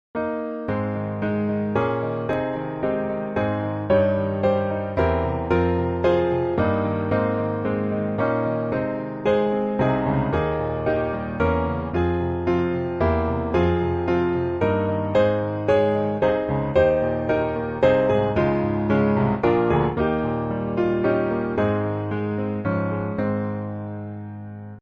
Piano Hymns
Ab Major